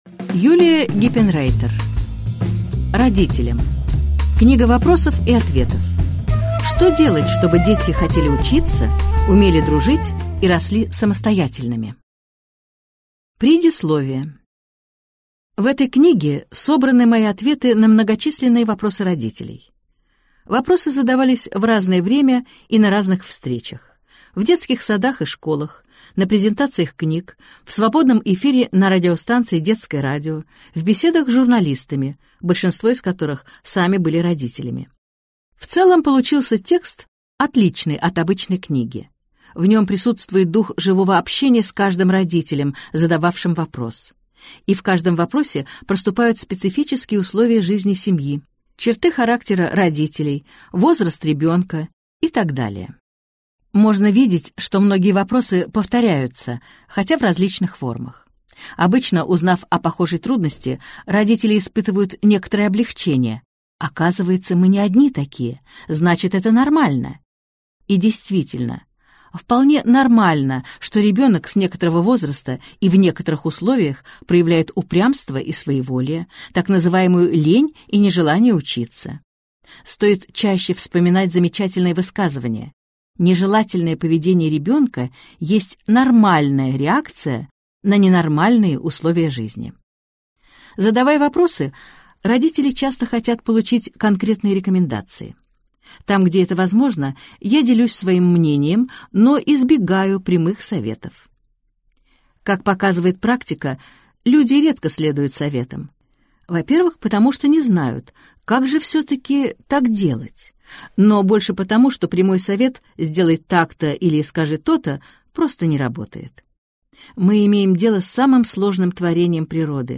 Аудиокнига Главная книга вопросов и ответов про вашего ребенка | Библиотека аудиокниг